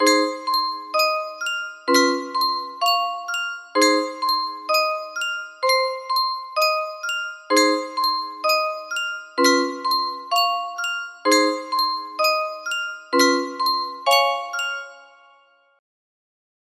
Yunsheng Music Box - Unknown Tune 1077 music box melody
Full range 60